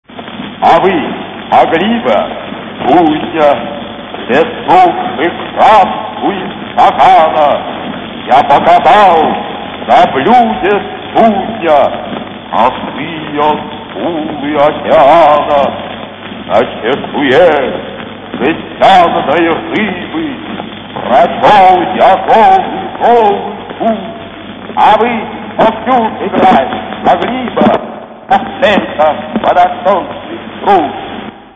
..А вы могли бы? Читает Автор - В.Маяковский
Чтение В.Маяковского какое-то трагическое, очень интересно произносит окончания слов.